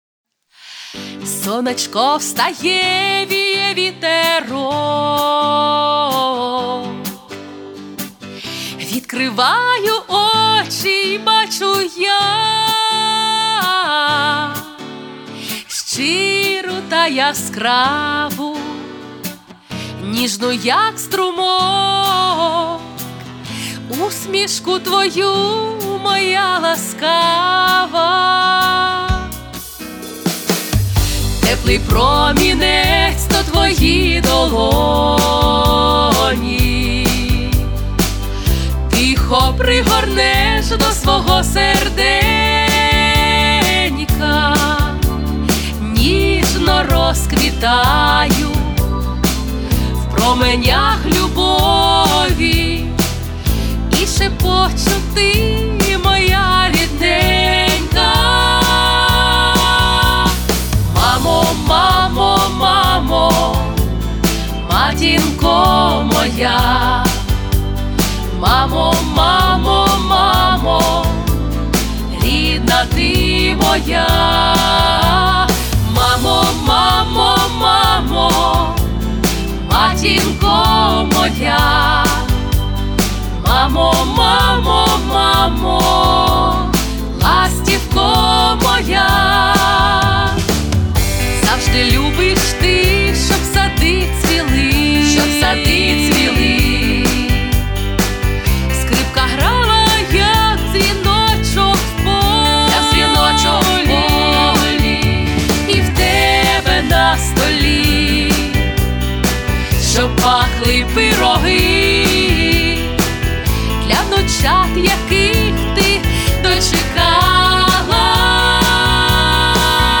3. Готове аудіо демо (всі партії разом).